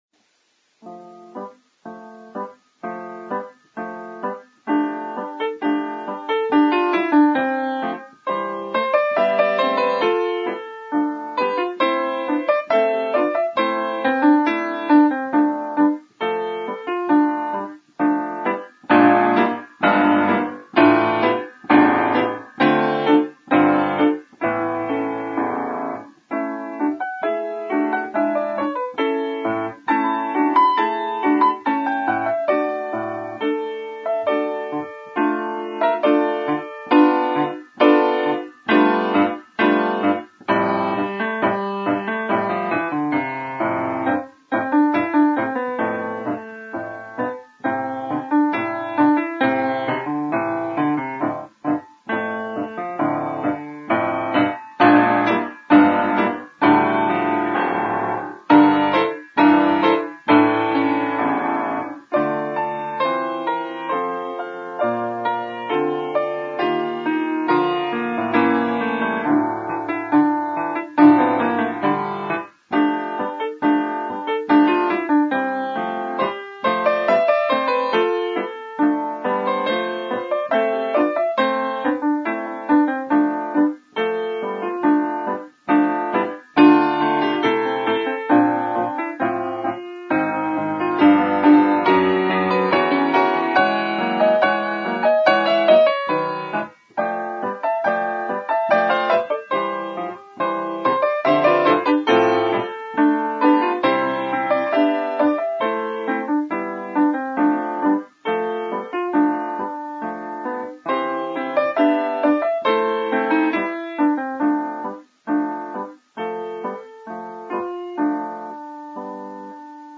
Piano Recital:
All piano solo:
PIANO COLLECTION: FOLKTUNES